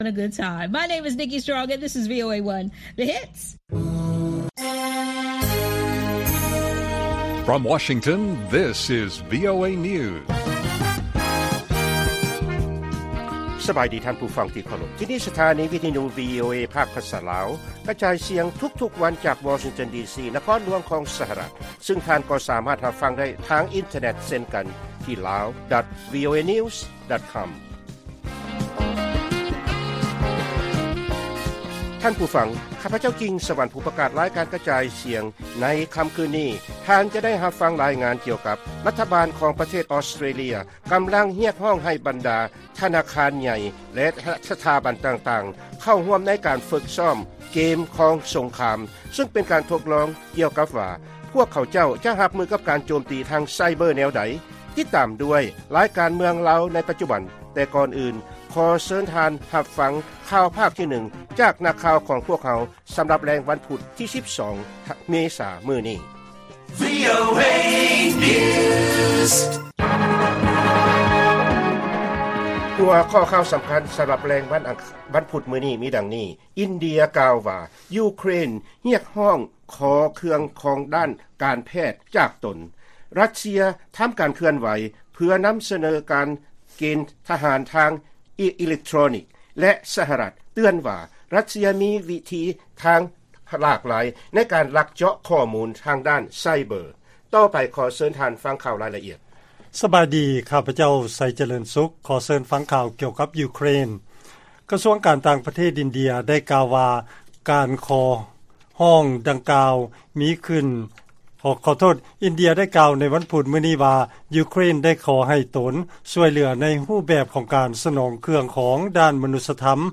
ວີໂອເອພາກພາສາລາວ ກະຈາຍສຽງທຸກໆວັນ, ຫົວຂໍ້ຂ່າວສໍາຄັນໃນມື້ນີ້ມີ: 1. ອິນເດຍ ກ່າວວ່າ ຢູເຄຣນ ຮຽກຮ້ອງຂໍເຄື່ອງຂອງດ້ານການແພດ ຈາກຕົນ, 2. ຣັດເຊຍ ທຳການເຄື່ອນໄຫວ ເພື່ອນຳສະເໜີການເກນທະຫານທາງ ອີເລັກໂທຣນິກ, ແລະ 3. ສະຫະລັດ ເຕືອນວ່າ ຣັດເຊຍ ມີວິທີທາງຫຼາກຫຼາຍ ໃນການລັກເຈາະຂໍ້ມູນ ທາງດ້ານໄຊເບີ.